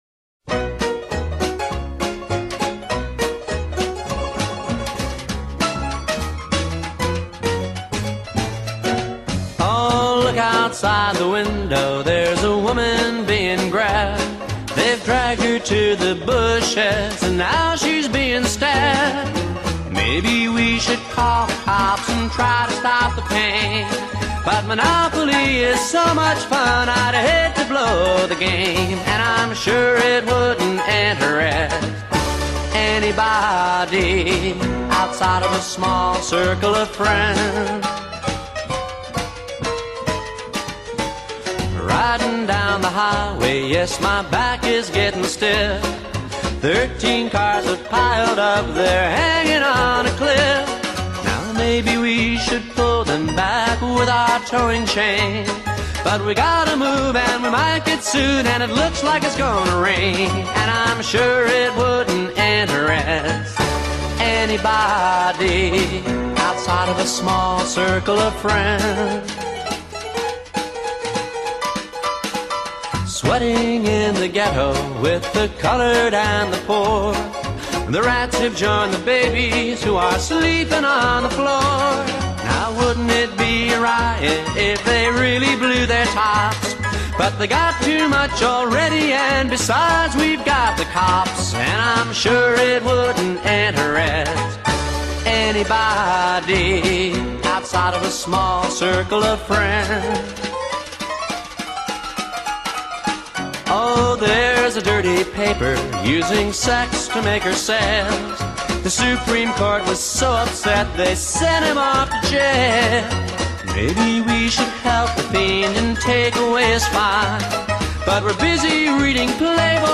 happy honky-tonk tune